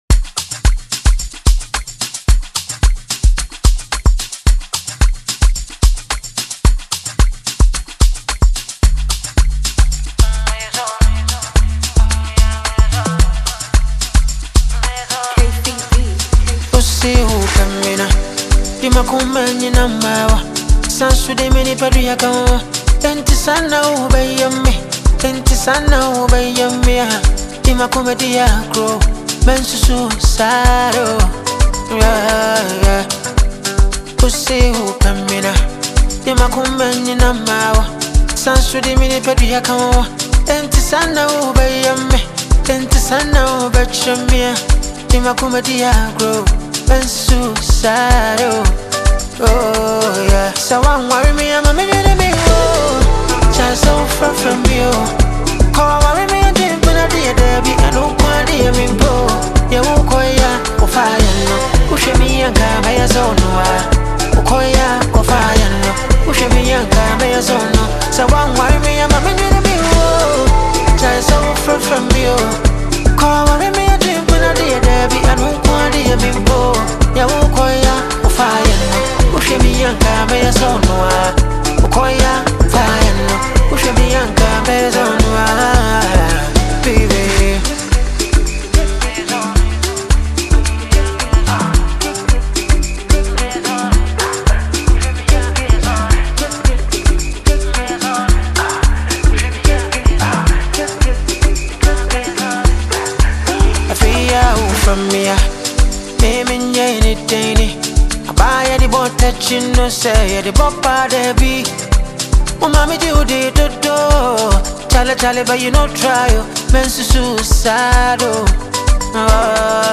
Genre: Highlife